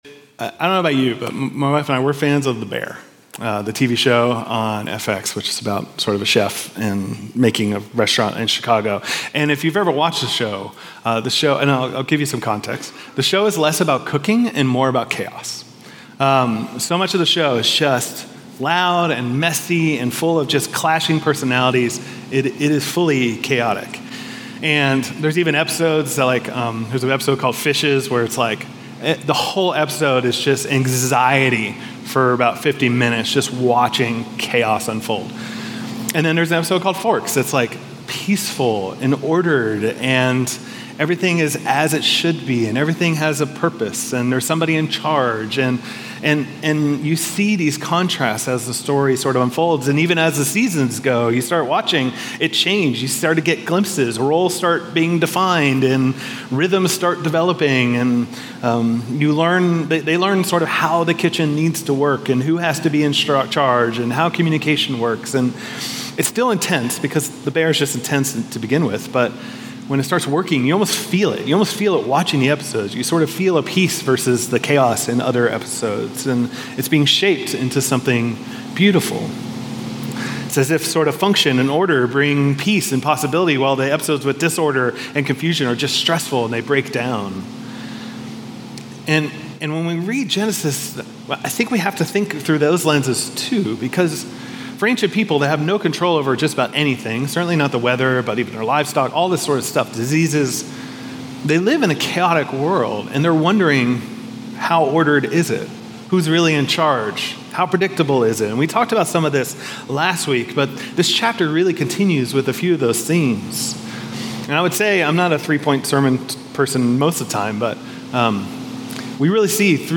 In Genesis 1, we meet a God who brings order out of disorder, fills creation with His presence, and declares it good. This sermon explores how the creation account isn’t just ancient poetry—it’s a lens for seeing our world and our lives today.